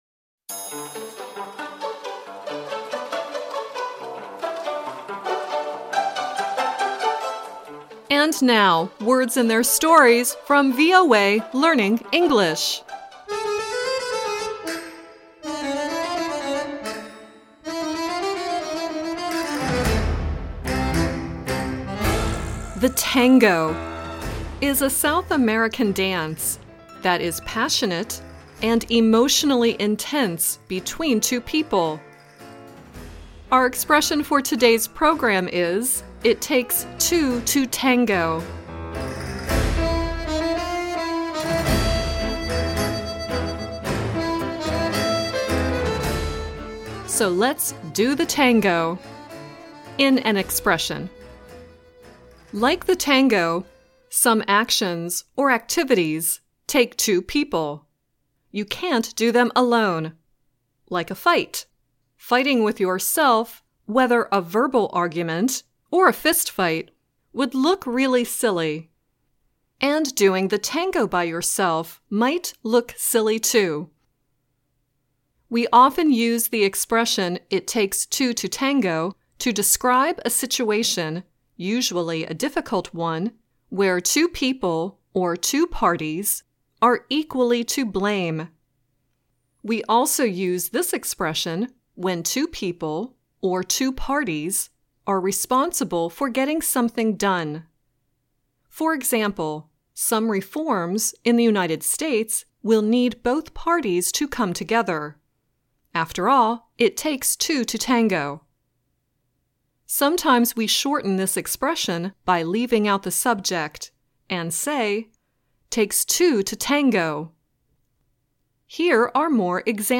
The song at the end is Louis Armstrong singing “It Takes Two to Tango.”